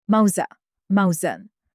banana-in-arabic.mp3